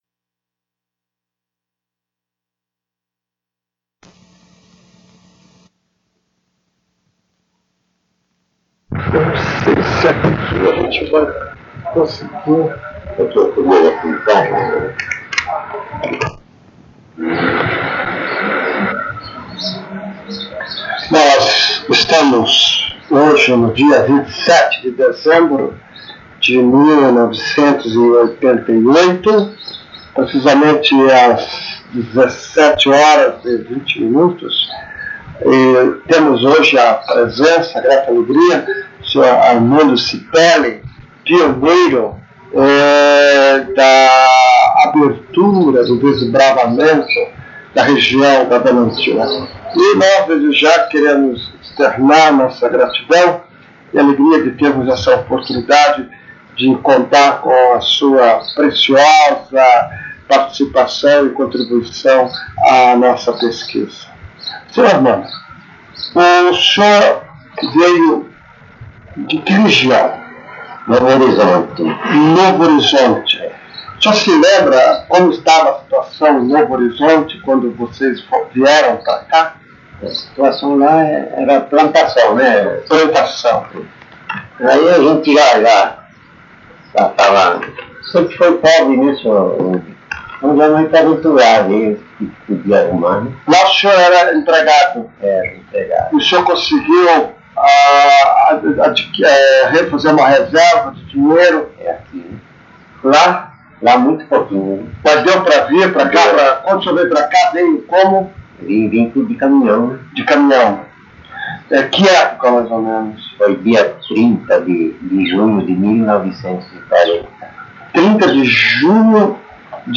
*Recomendado ouvir utilizando fones de ouvido.